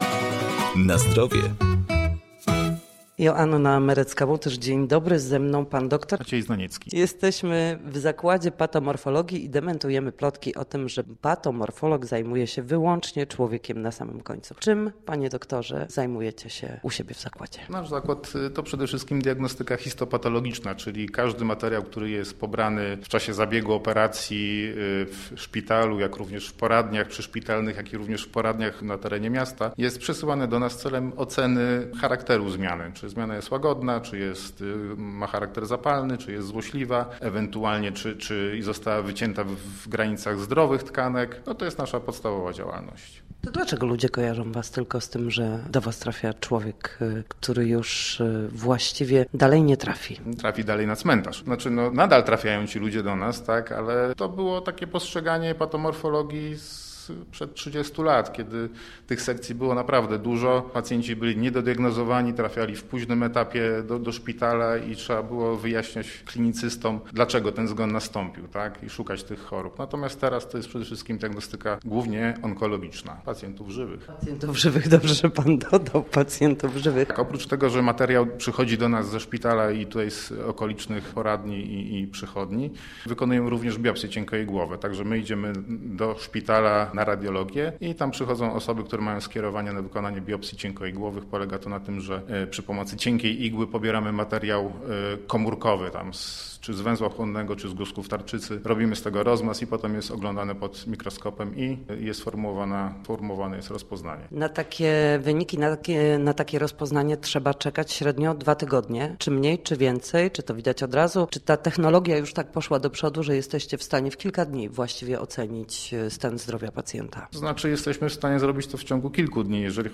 Nasi goście, lekarze, fizjoterapeuci, w audycji „Na zdrowie”, będą odpowiadać na pytania, dotyczące najczęstszych dolegliwości.
Posłuchaj rozmowy z patomorfologiem